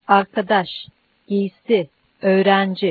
Usually, nouns that are general in nature -- take the accent on the last syllable.